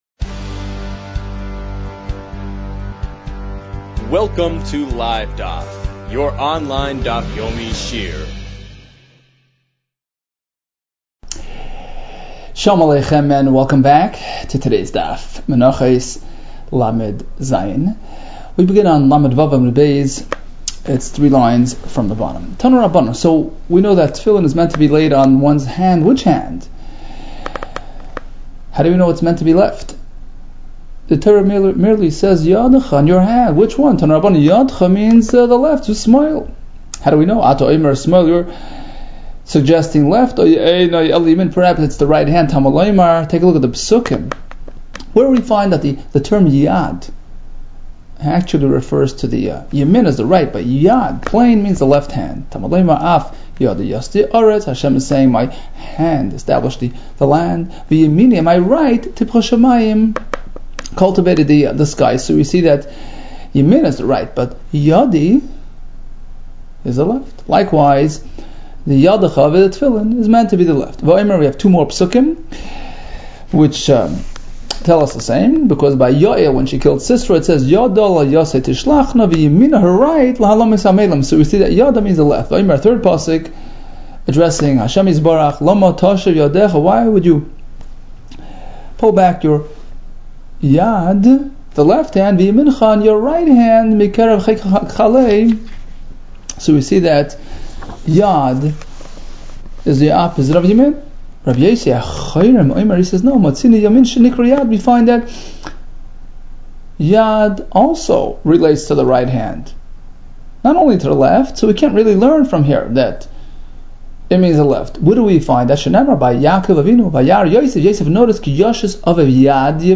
Menachos 37 - מנחות לז | Daf Yomi Online Shiur | Livedaf